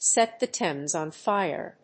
アクセントsèt the Thámes [wórld] on fíre